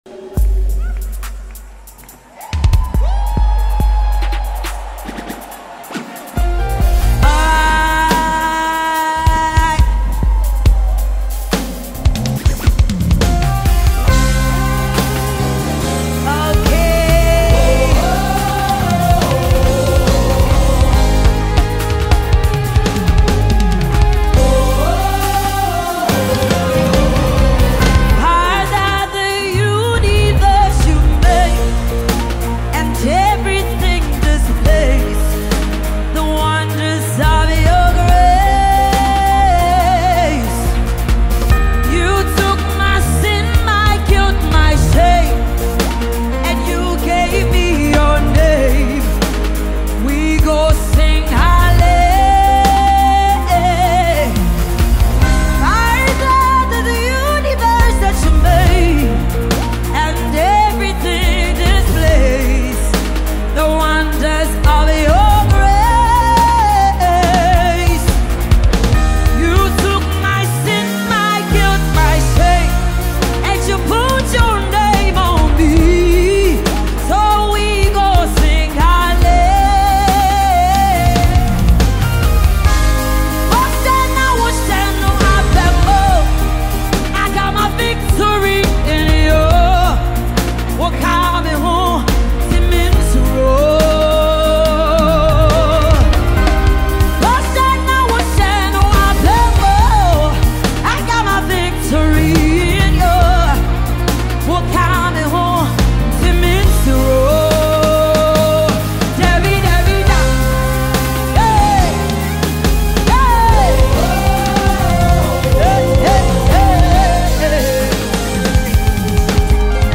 A dynamic gospel music group
a mesmerising melody